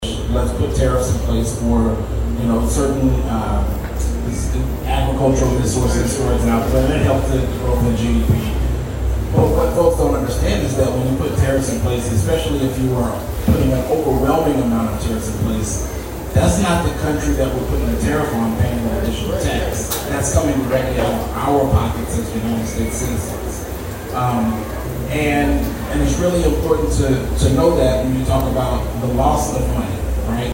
in the town hall the Democrat Party hosted on Saturday